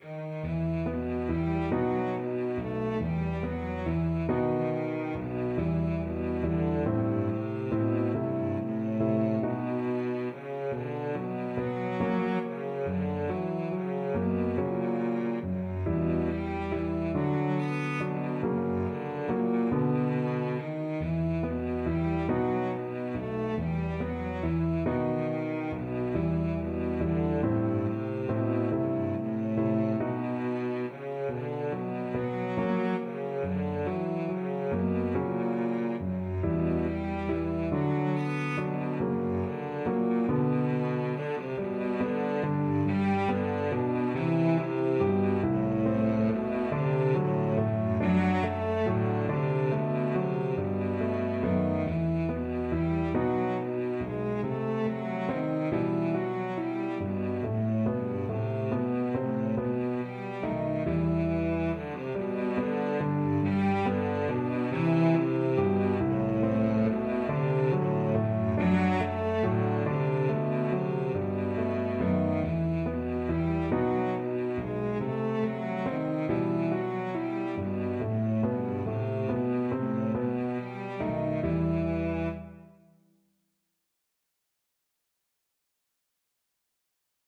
Flowing = c.140
6/4 (View more 6/4 Music)